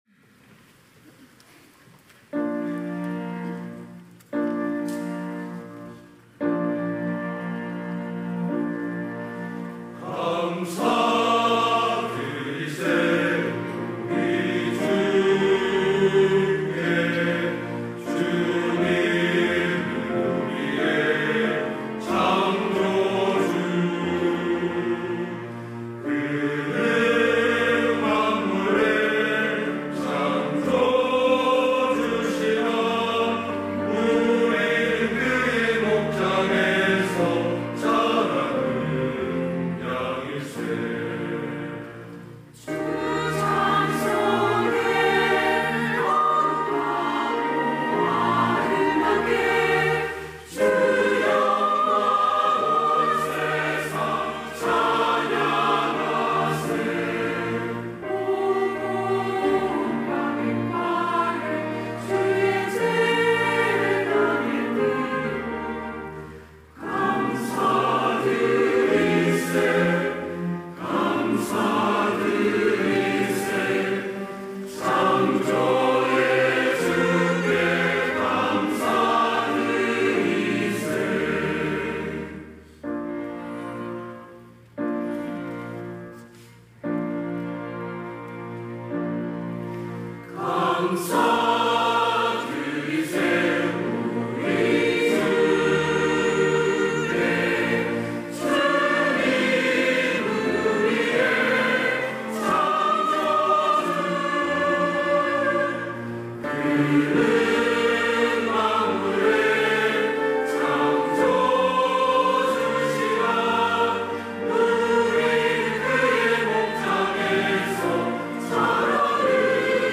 호산나(주일3부) - 주님께 감사하라
찬양대